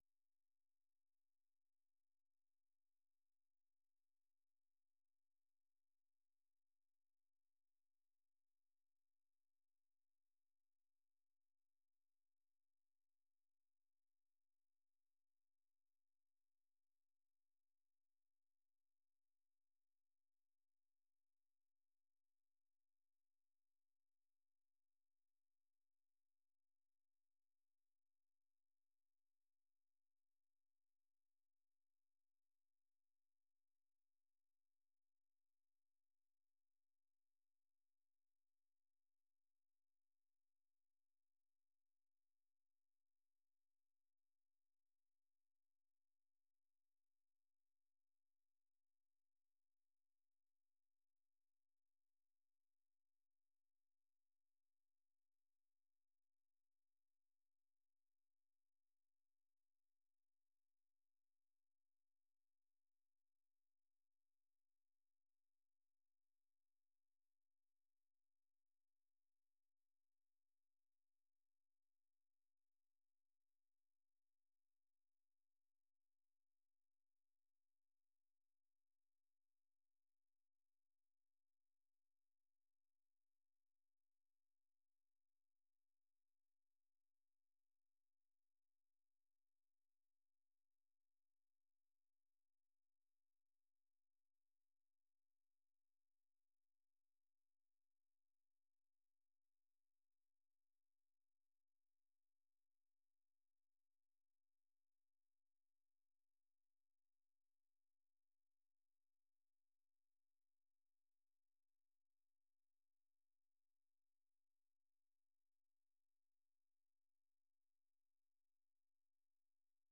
생방송 여기는 워싱턴입니다 저녁